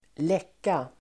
Uttal: [²l'ek:a]